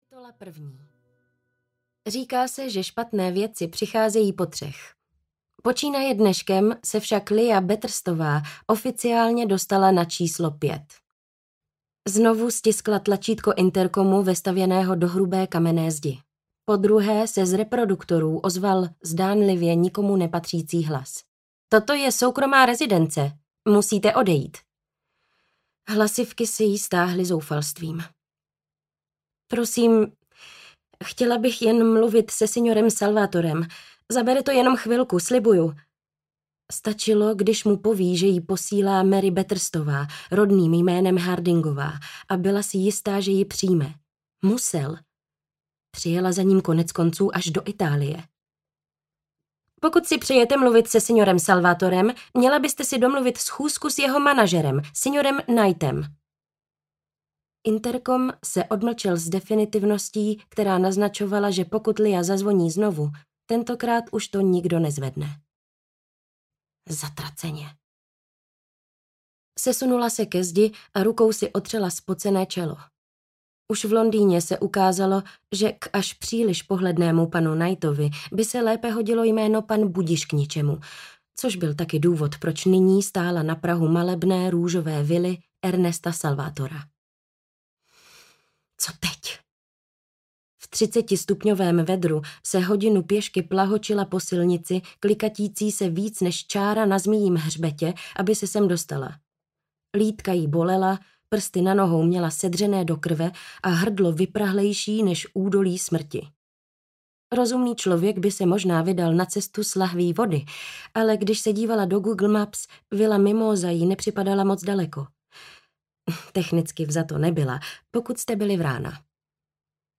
Vila v Itálii audiokniha
Ukázka z knihy